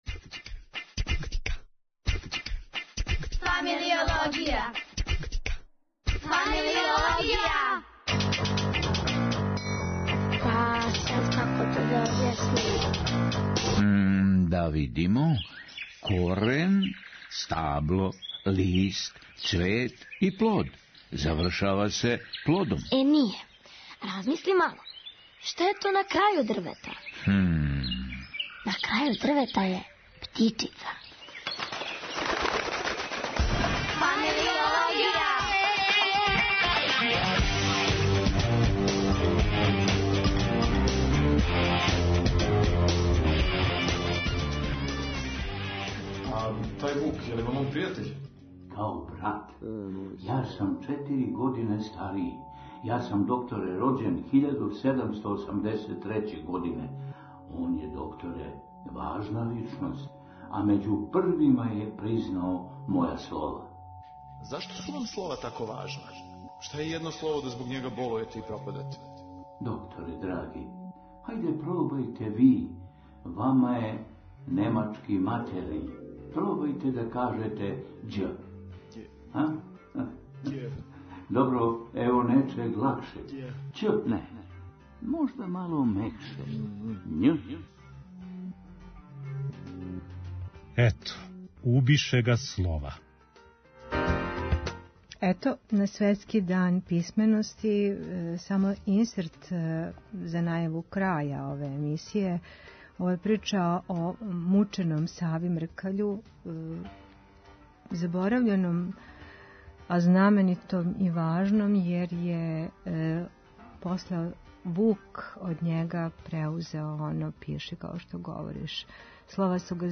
Наша гошћа